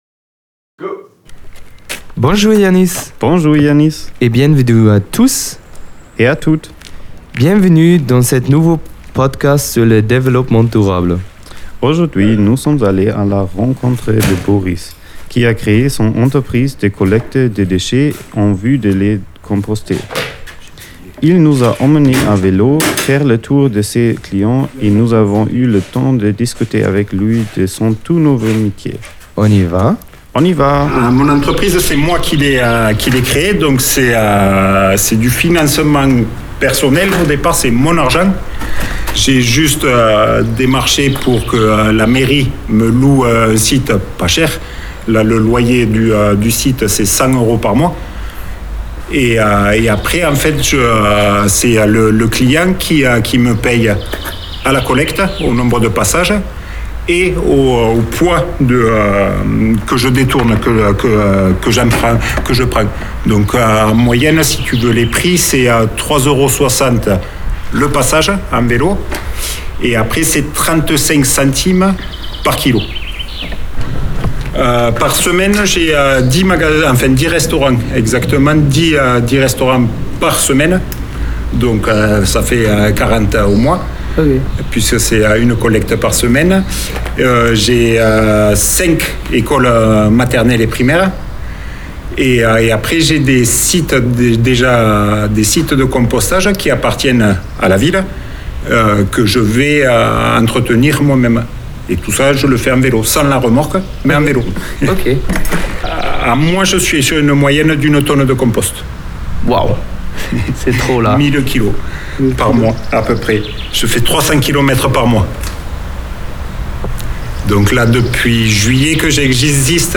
« Reportages » en podcast : « Ô Compost »